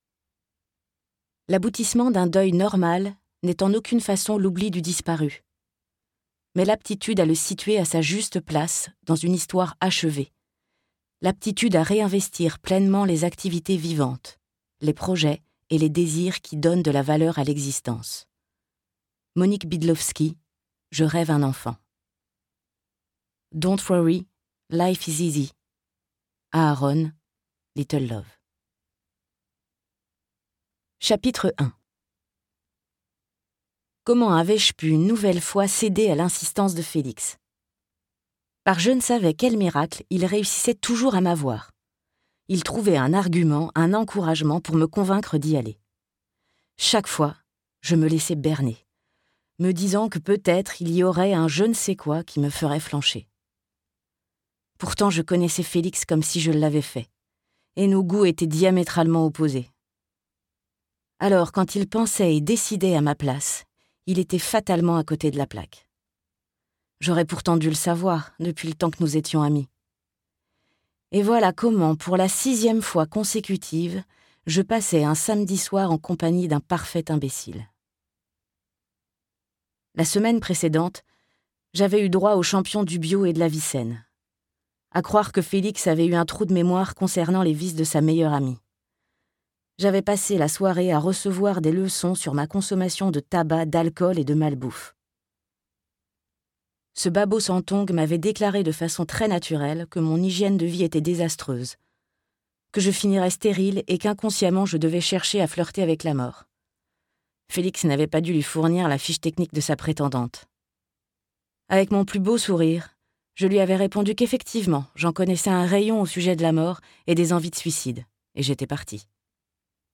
Livre audio lu par l'autrice.